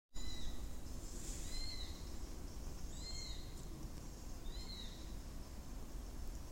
Audioaufnahmen aus dem Schutzgebiet